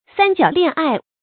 發音讀音
成語簡拼 sjla 成語注音 ㄙㄢ ㄐㄧㄠˇ ㄌㄧㄢˋ ㄞˋ 成語拼音 sān jiǎo liàn ài 發音讀音 常用程度 常用成語 感情色彩 中性成語 成語用法 作主語、賓語、定語；用于男女關系 成語結構 偏正式成語 產生年代 當代成語 成語例子 路遙《平凡的世界》第四卷第52章：“‘我不愿意聽你們的 三角戀愛 故事！’